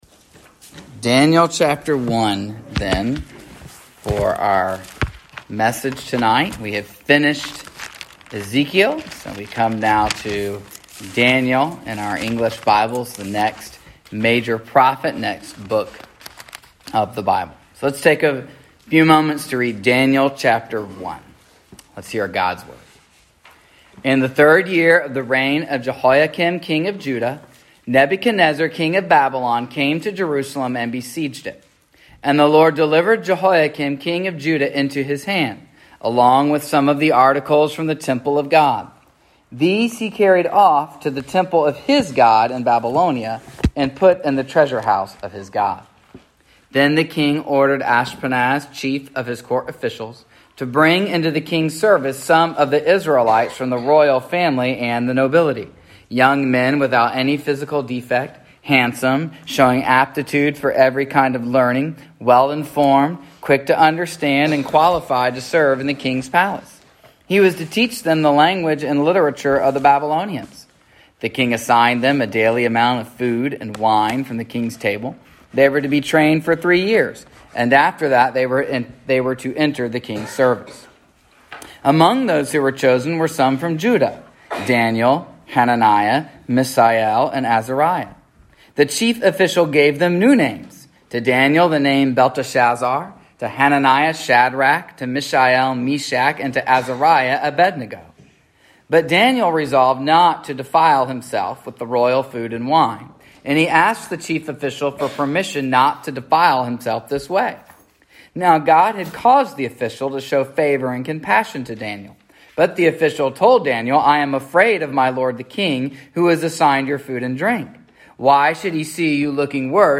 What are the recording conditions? Service Type: Sunday Evening